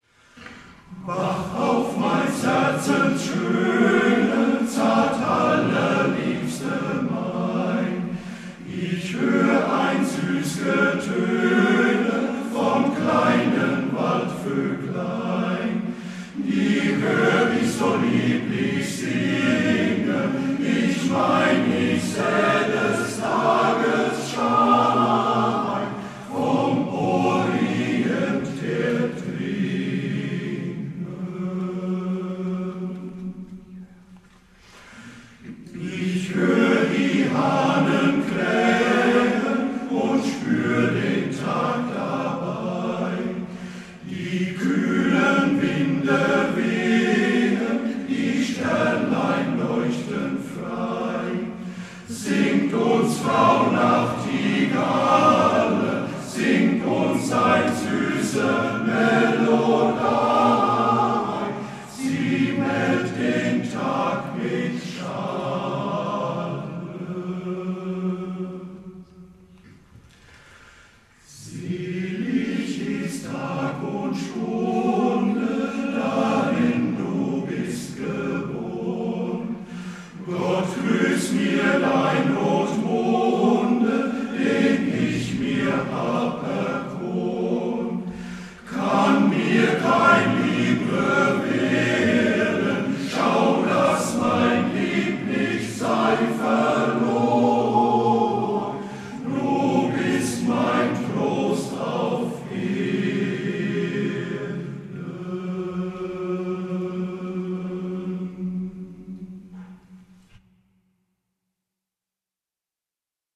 Chormusik
Die folgenden Mitschnitte entstanden auf dem Volksliederwettbewerb 2014 in Bernbach.